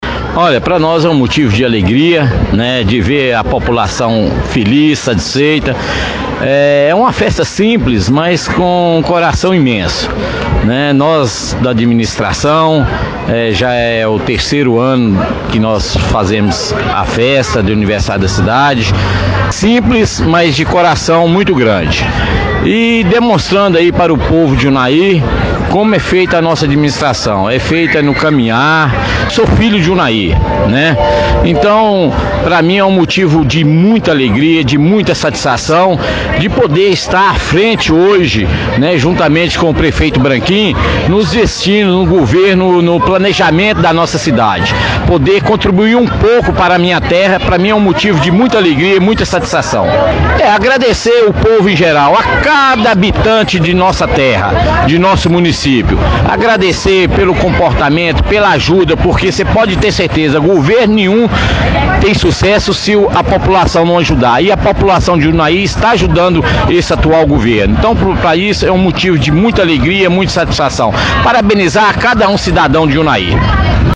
Nossa reportagem esteve presente e ouviu as autoridades que participaram ativamente das atividades.